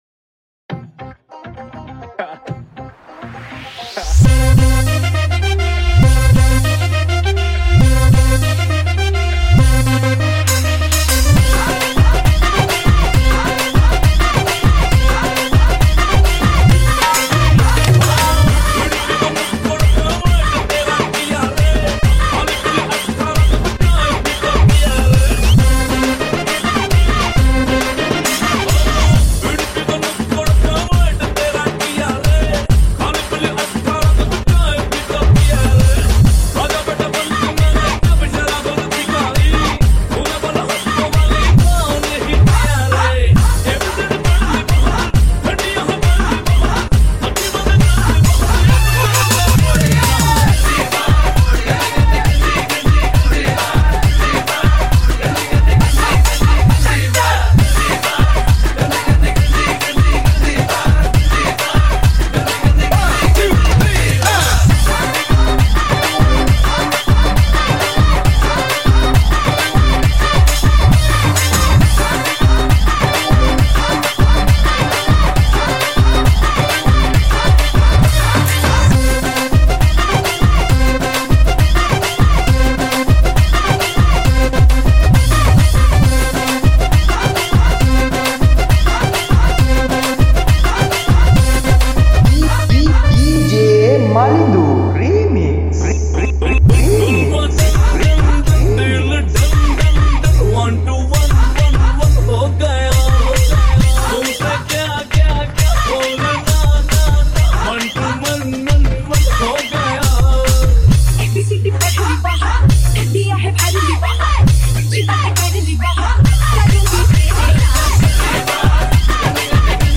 High quality Sri Lankan remix MP3 (10).